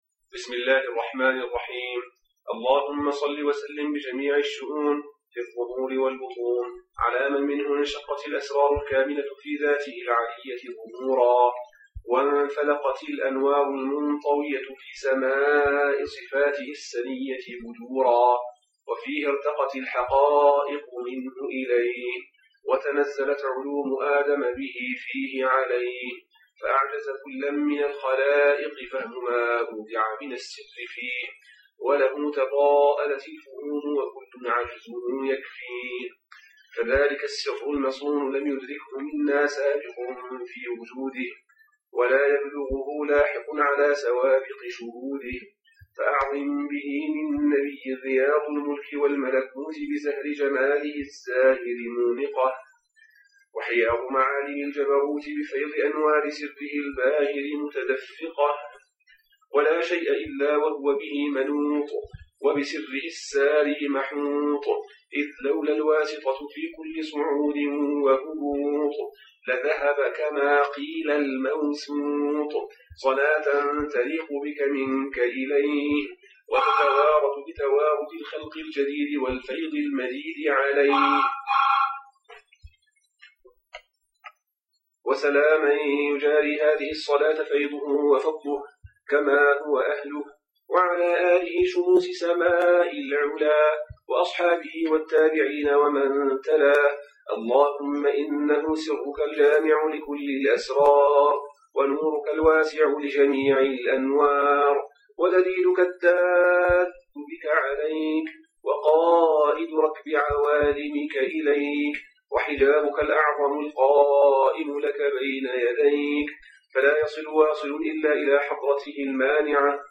Recited by Shaykh Muhammad al-Yaqoubi (2010)
Muhammad al-Yaqoubi 🞄 Language: English · عربي 🞄 Mediatype: Ahzab · Audio · Shadhili Litanies 🞄 Related Posts Bio: Sh.